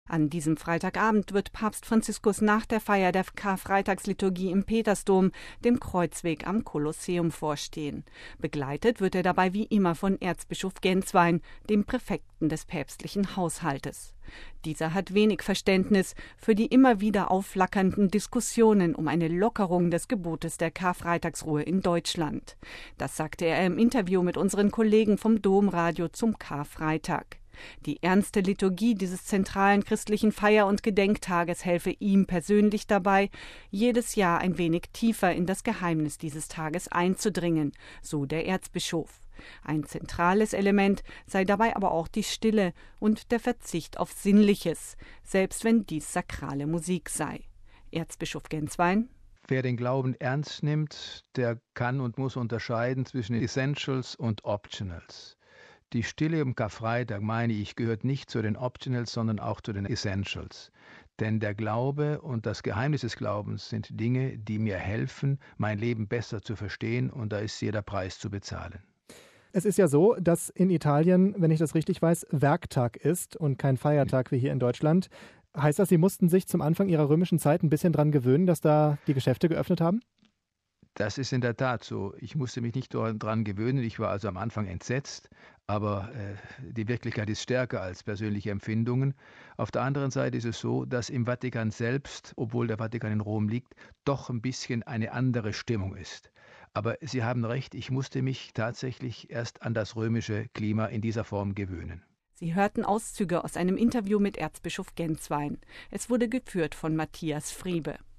Der deutsche Kurienerzbischof Georg Gänswein hat wenig Verständnis für die immer wieder aufflackernden Diskussionen um eine Lockerung des Gebotes der Karfreitagsruhe in Deutschland. Das sagte er im Interview mit unseren Kollegen vom Domradio zum Karfreitag.